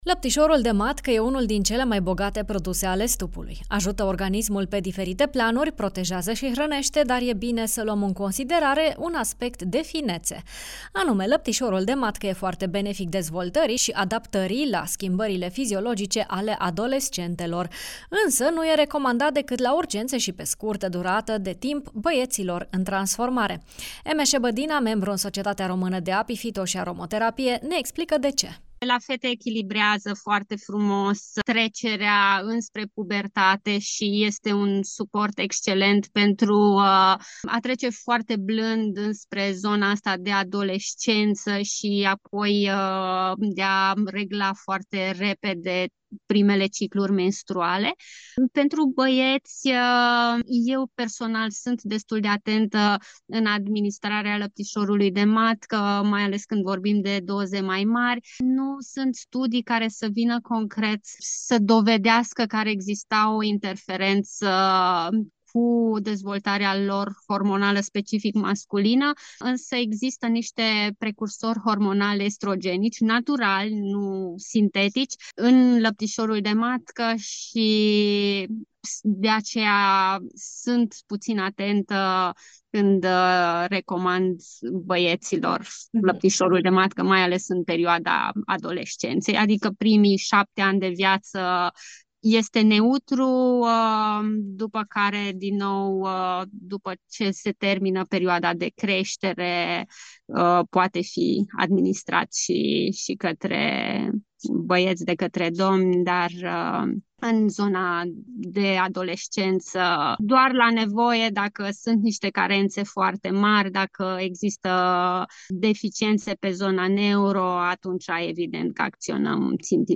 specialist in apiterapie: